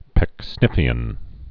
(pĕk-snĭfē-ən)